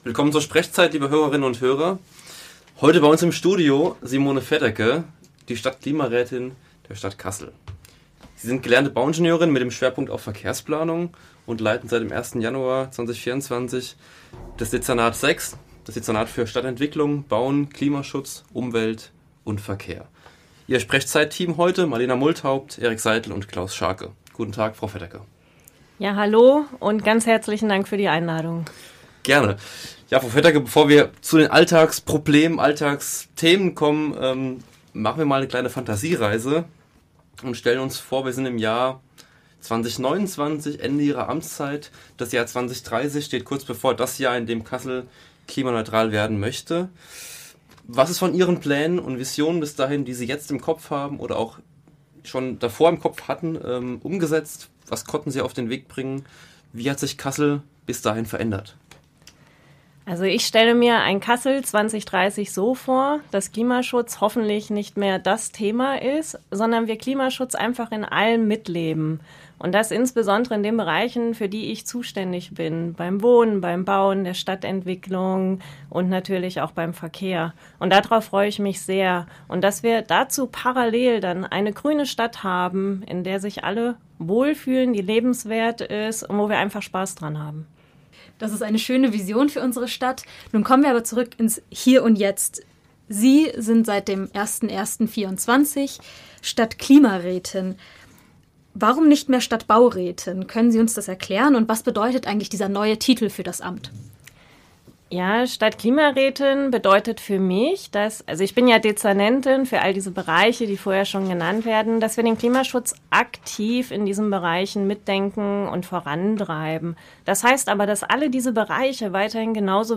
Nach knapp vier Monaten im Amt wirft das SprechZeit-Team im Gespräch mit Simone Fedderke einen Blick in die Zukunft, fragt nach, zu Ideen und konkreten Projekten, und den Schwerpunkten, die sie als Stadtklimarätin während ihrer Amtszeit mit ihrem Team angehen möchte. Dieser SprechZeit-Podcast wurde am 25.4.2024 beim Freien Radio Kassel aufgezeichnet.